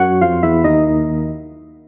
music are now 16bits mono instead of stereo
defeat.wav